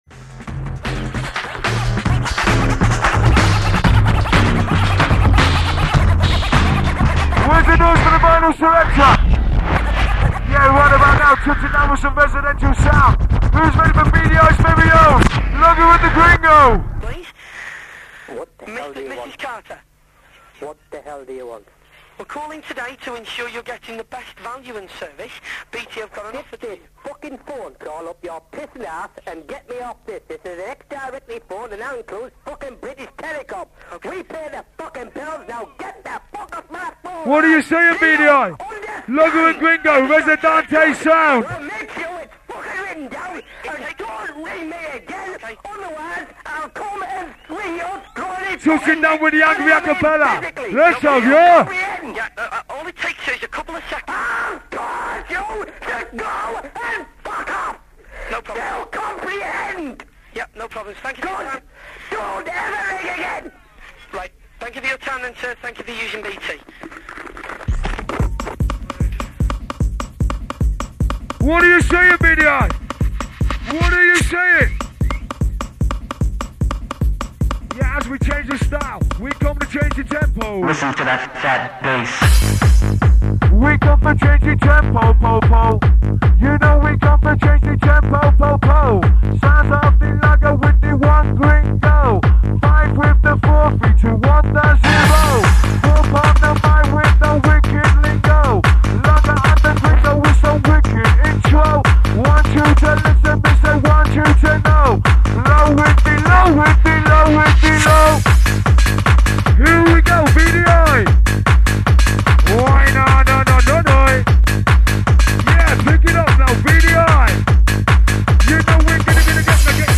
Live DJ Sets
Hard Trance / Hard House